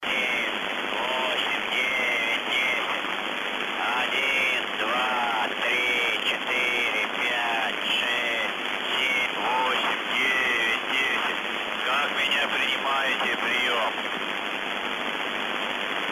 Счет на частоте 7322.5 Ижевск?